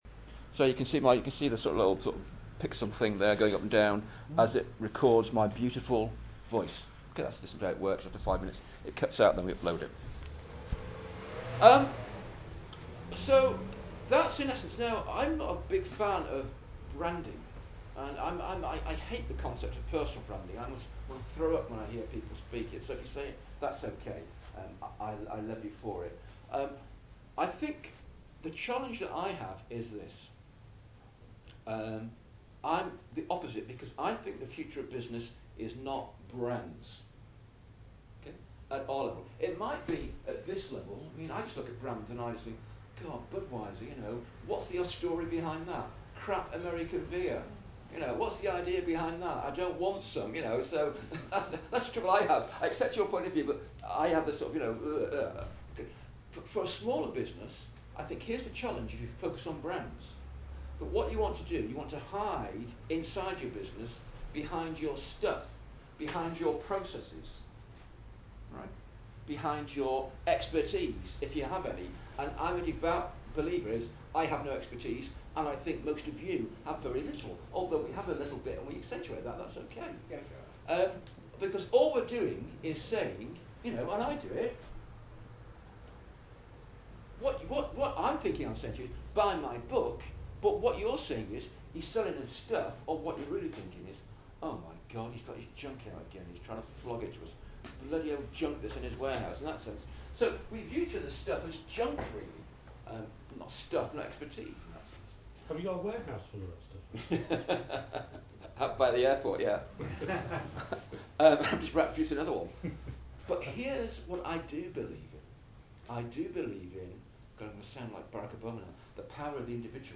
Anti Branding Speech
46361-anti-branding-speech.mp3